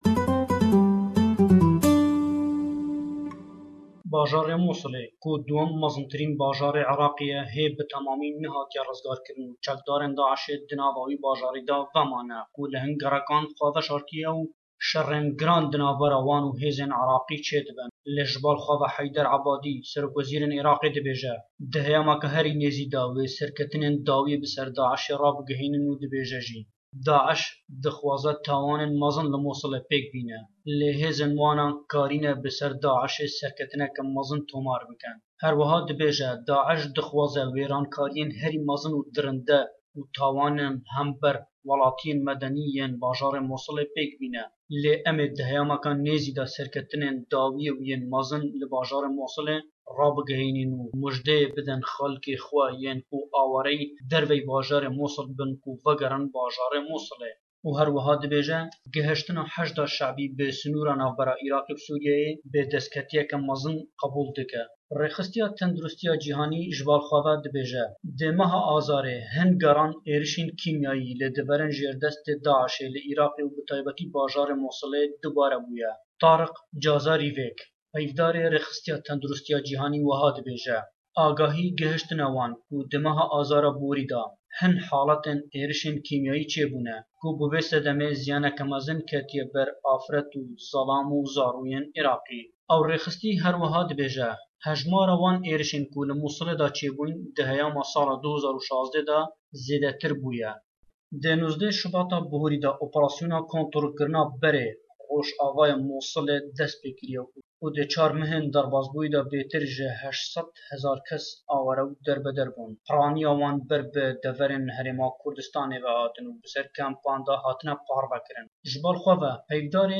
Raporta ji Hewlêr, sherê bo vegerandina Mûsilê berdewame